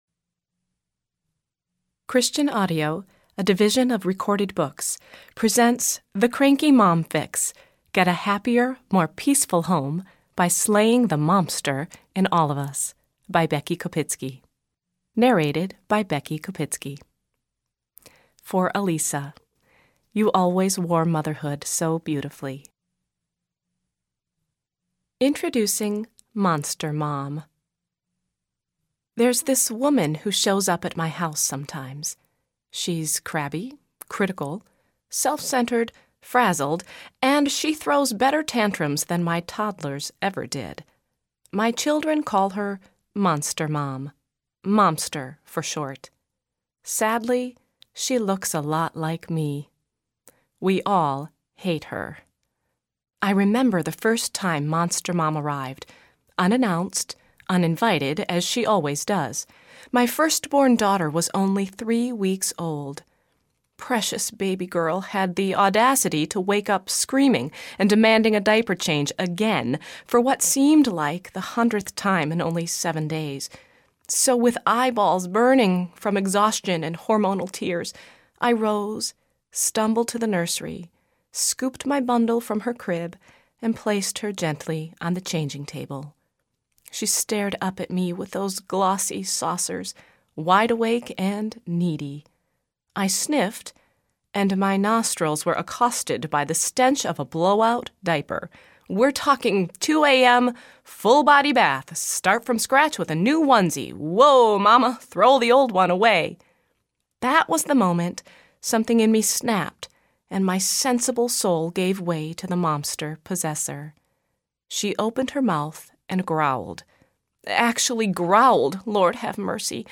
Cranky Mom Fix Audiobook
6.95 Hrs. – Unabridged